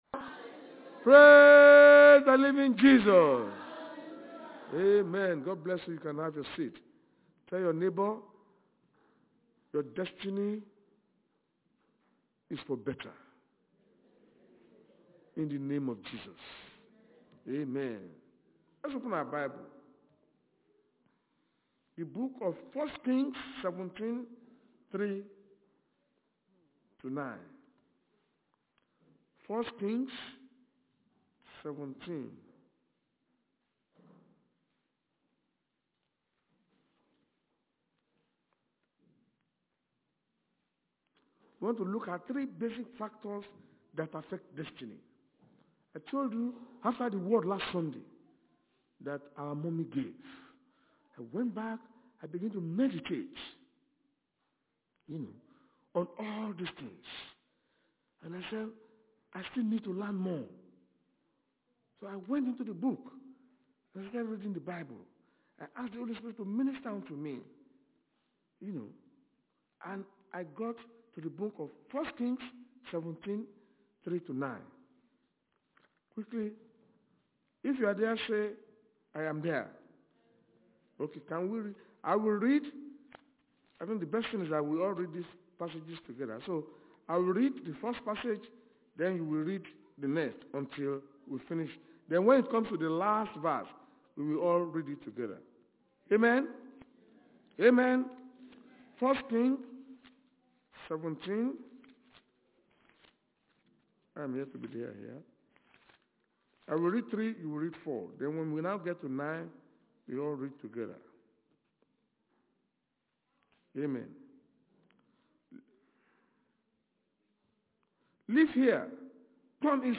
Service Type: Sunday Church Service